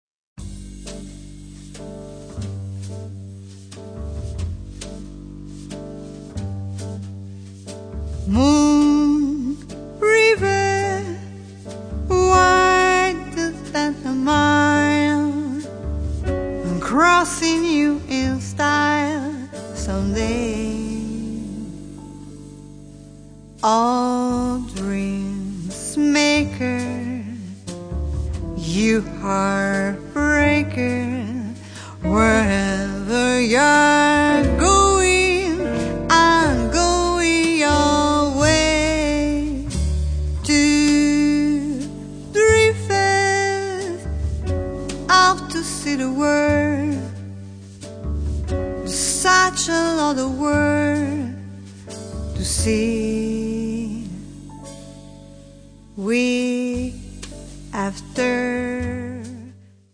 vocals
piano
alto saxophone
trumpet, flugelhorn
guitar
bass
drums